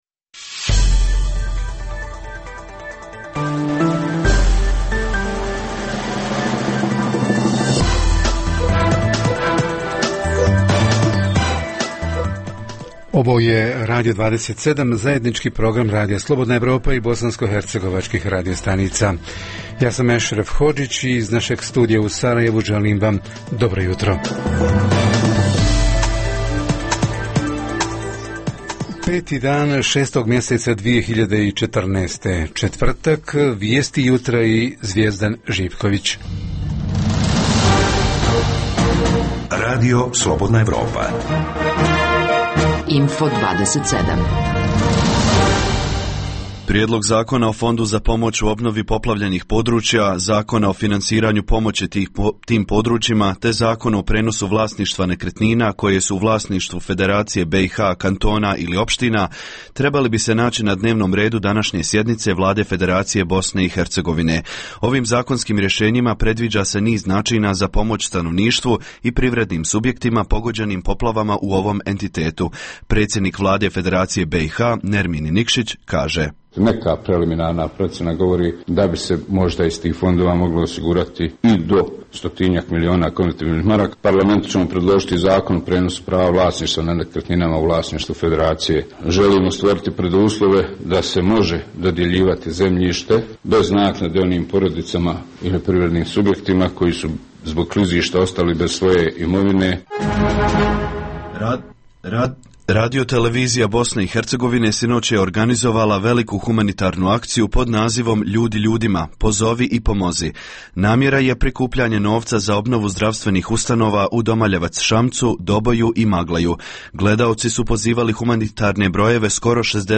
O tome javljaju naši reporteri iz Bijeljine i Travnika.